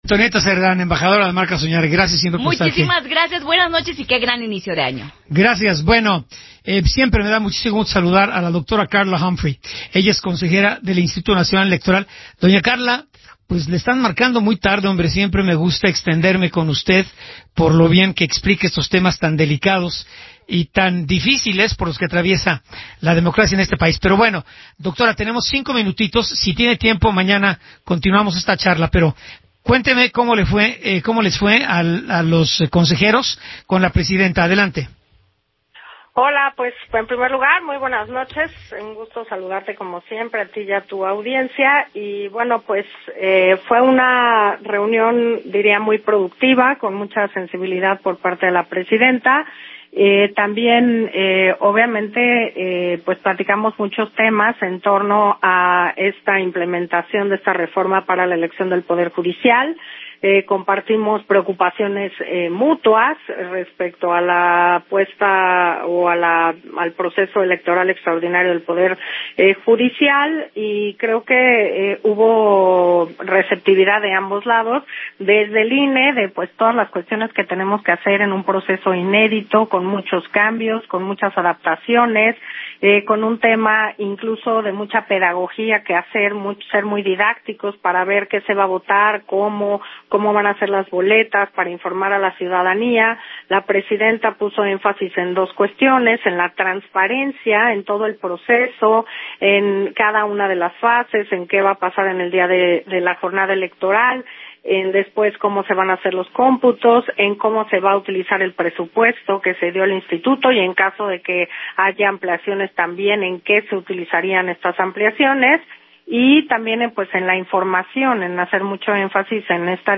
Entrevista de la Consejera Electoral Carla Humphrey con Óscar Mario Beteta para Heraldo Radio